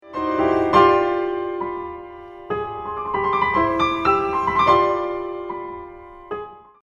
Now, go back to the Beethoven-sighs and you will find them in both hands, pretty much everywhere, too: